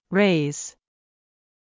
raise /réiz/「上げる」＜自動詞＞